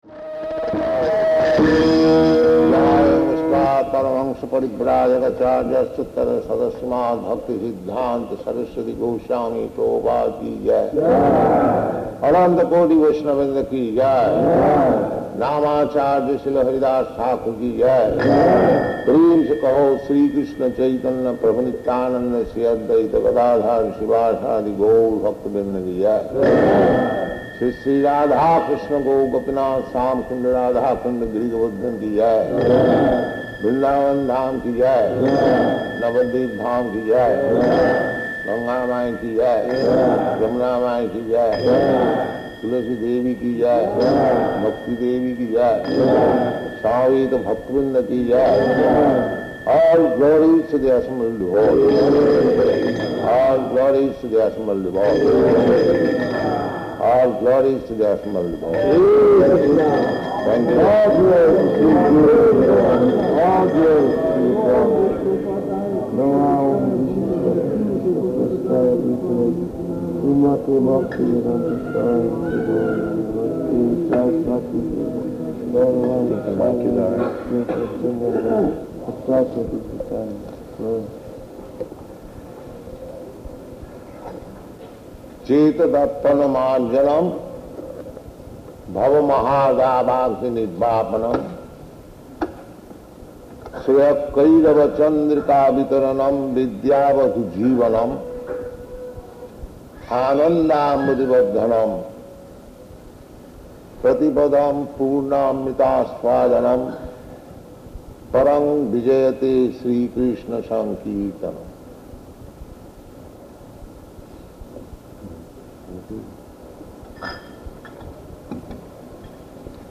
Lecture
Lecture --:-- --:-- Type: Lectures and Addresses Dated: May 10th 1972 Location: Honolulu Audio file: 720510LE.HON.mp3 Prabhupāda: [ prema-dhvani ] Thank you very much.